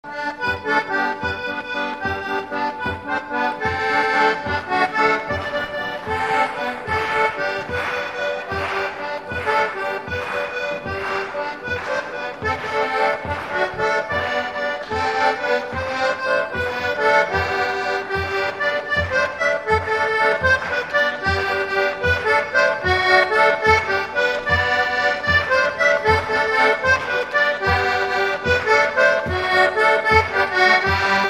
Valse
Instrumental
danse : valse
Pièce musicale inédite